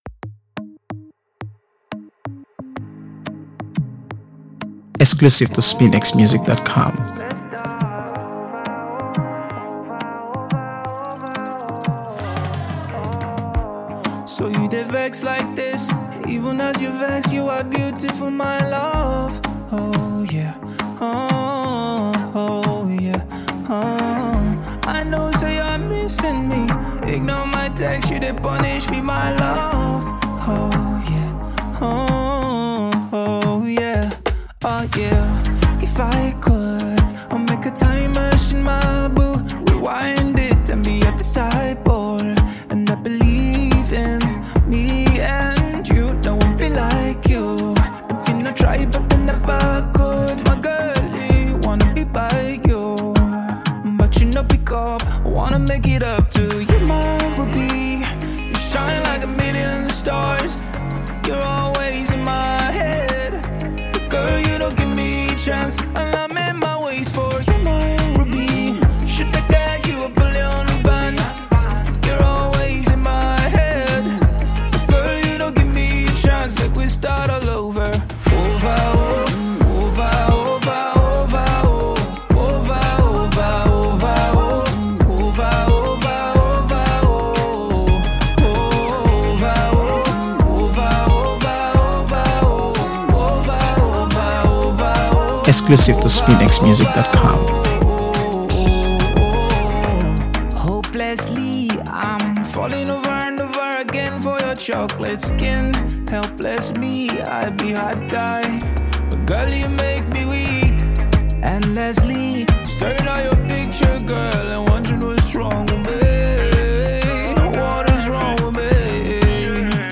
Afro-Pop song